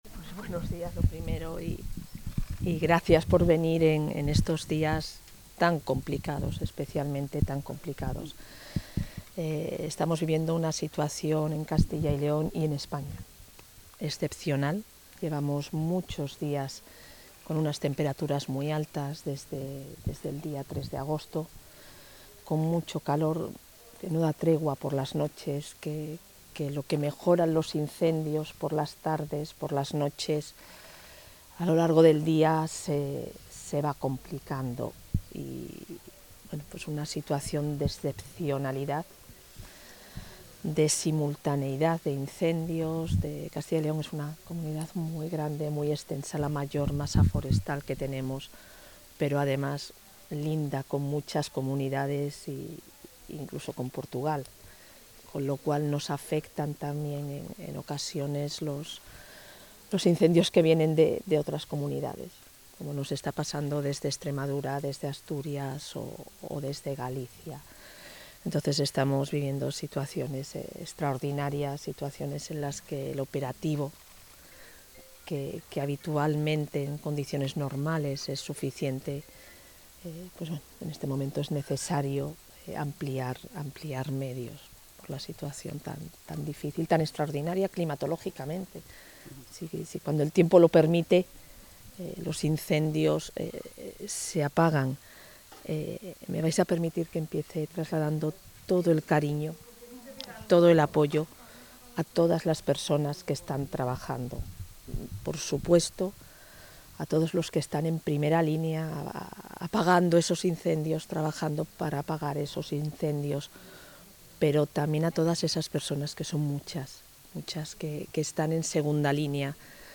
Declaraciones de la vicepresidenta.